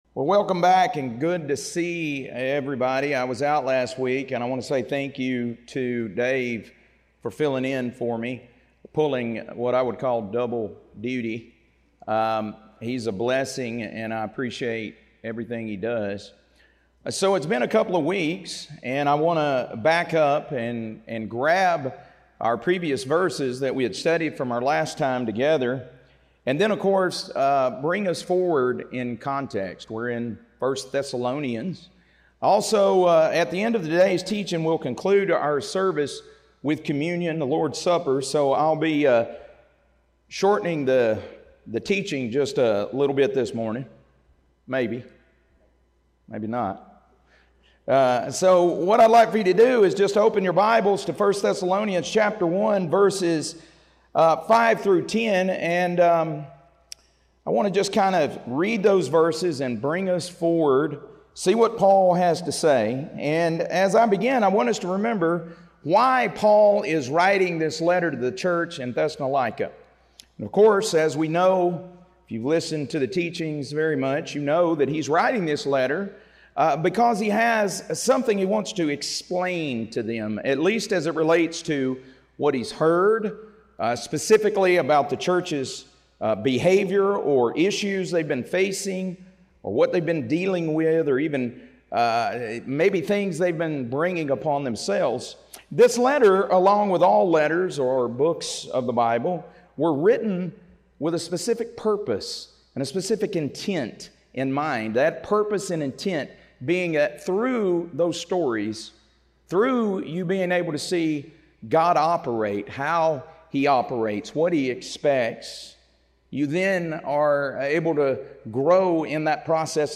1 Thessalonians - Lesson 2A | Verse By Verse Ministry International